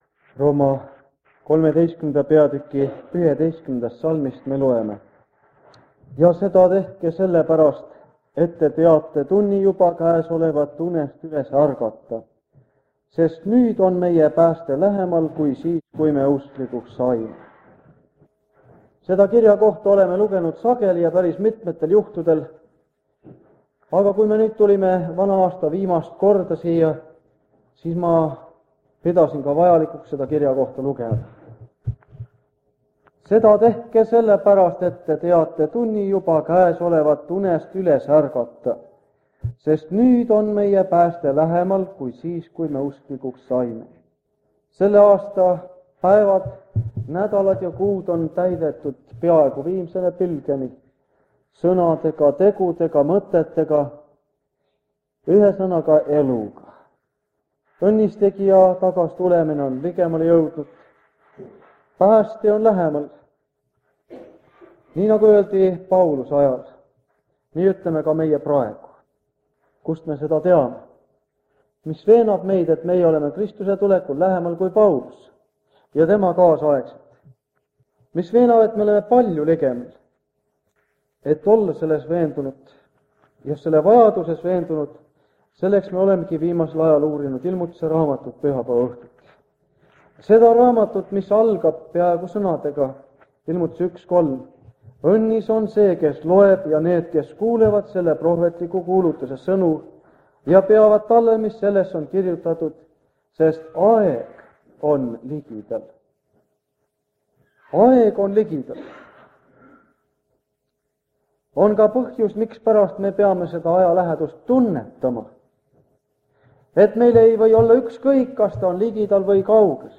Ilmutuse raamatu seeriakoosolekud Kingissepa linna adventkoguduses
Jutlused